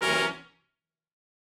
GS_HornStab-C7b2b5.wav